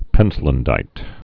(pĕntlən-dīt)